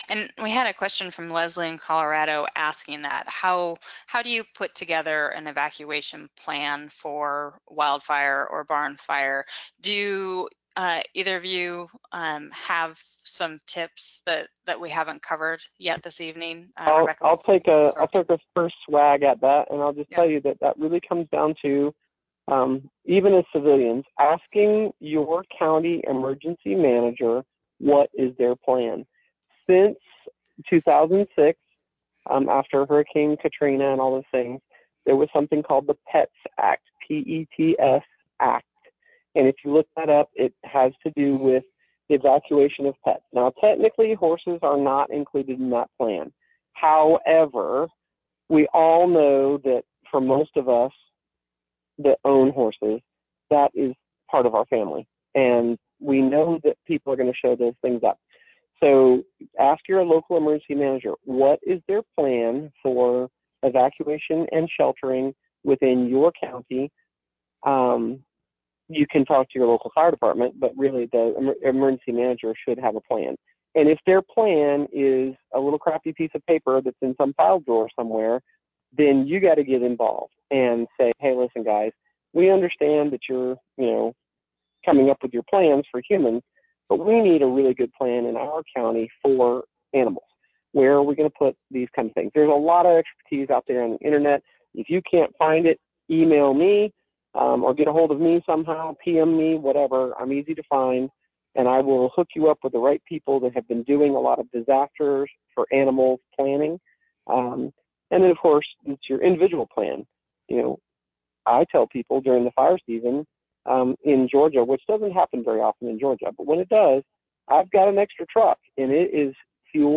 This podcast is an excerpt from our Ask TheHorse Live Q&A, “Protecting Your Horse Farm From Fires.”